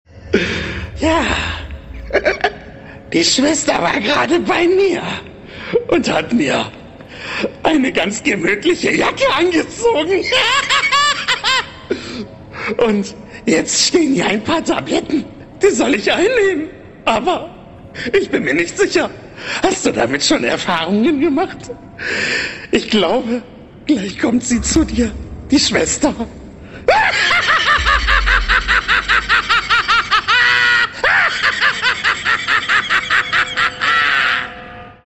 Werbesprecher